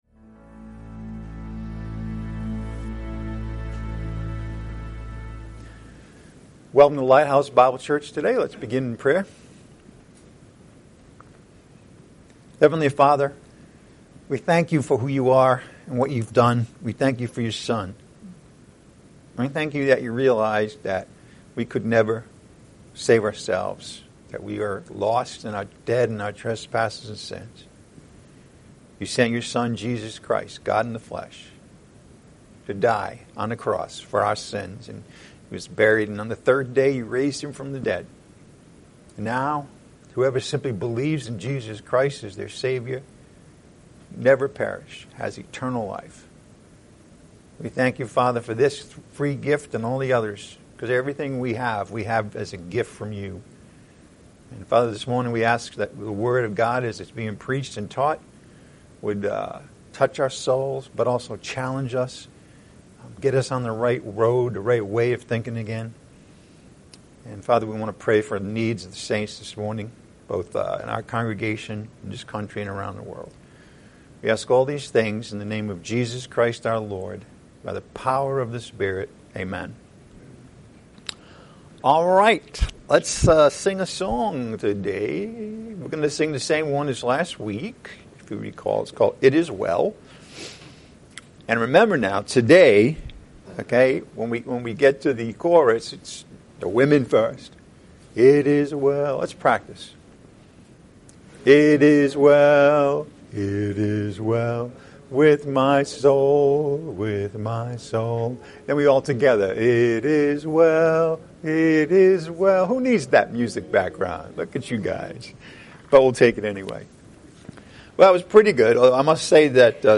Lighthouse Bible Church (LBC) is a no-nonsense, non-denominational, grace oriented and Bible centered Christian church.